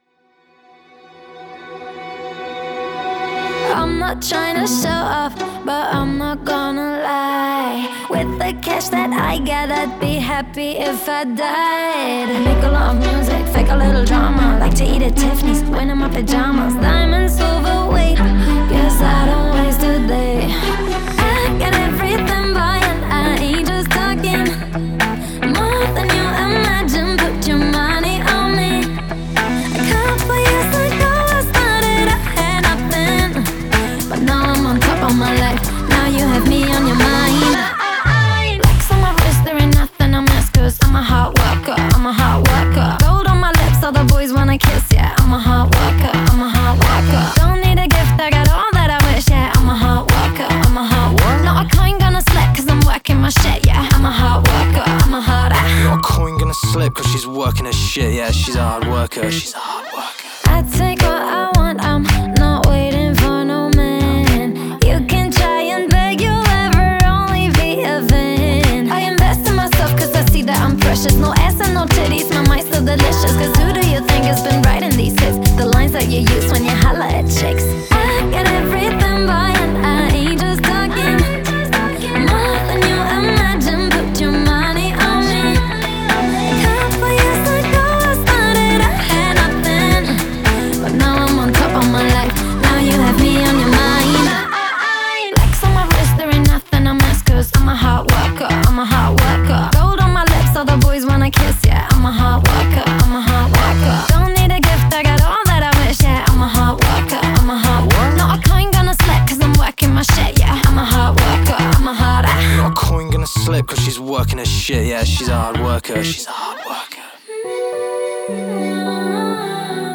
это энергичная композиция в жанре хип-хоп